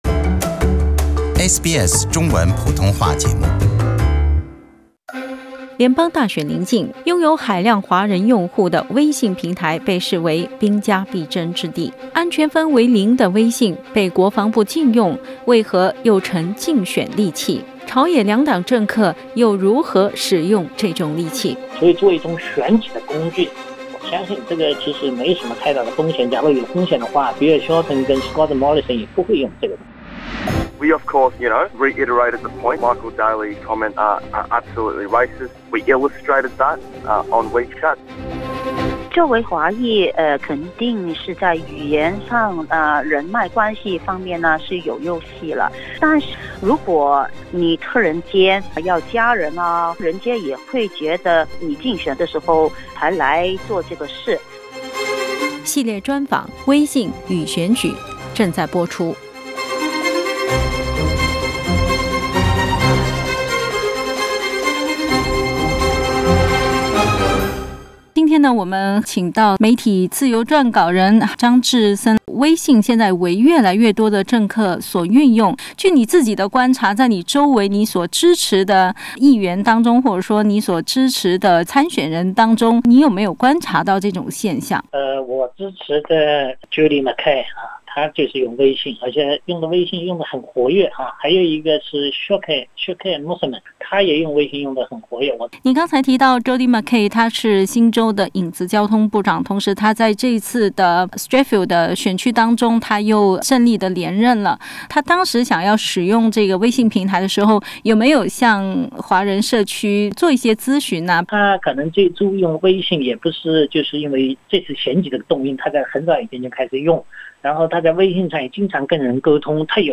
听众朋友，欢迎收听SBS普通话电台制作的特别节目 - 系列专访“微信与选举”。